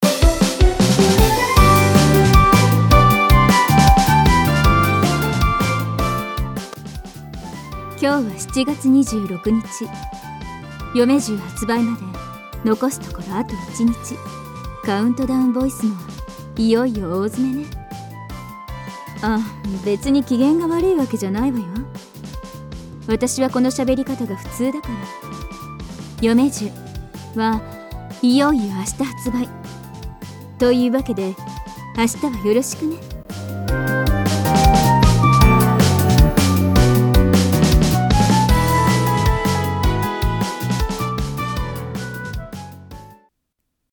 カウントダウンボイス1日前！